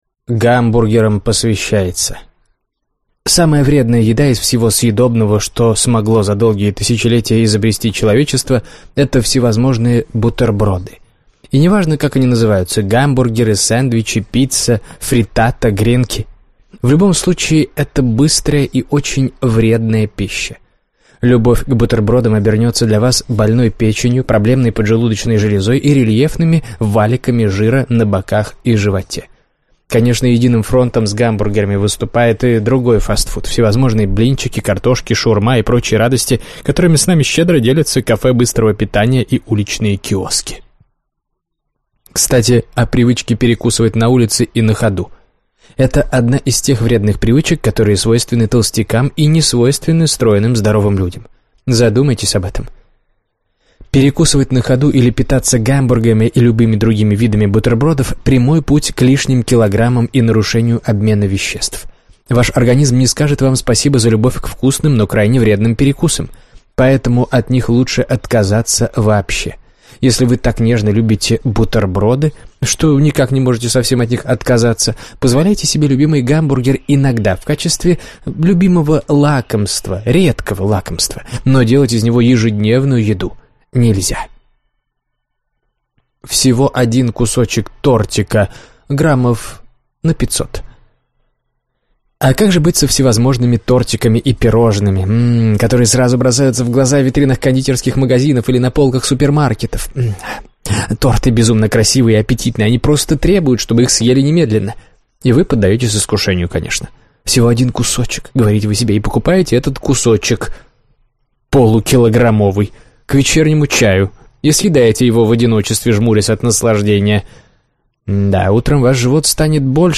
Аудиокнига Привычки толстых. Диета наоборот | Библиотека аудиокниг